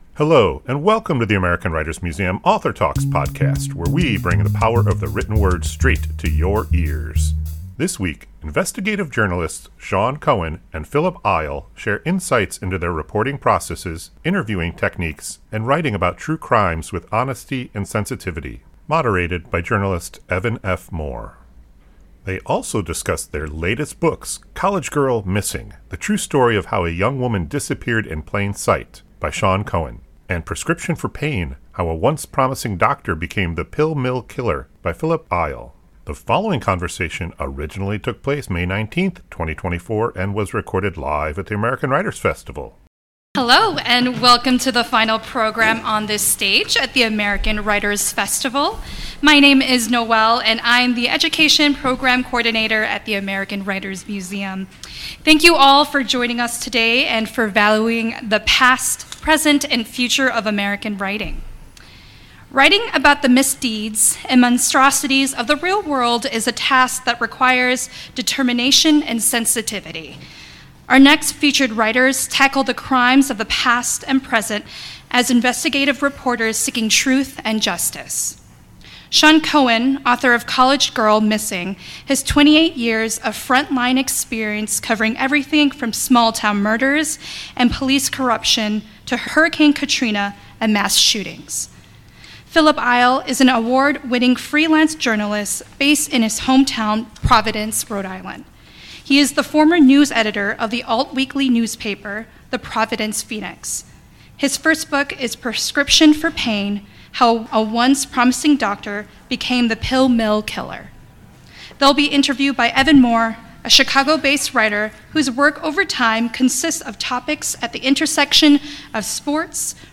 AWM Author Talks